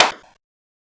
slap.ogg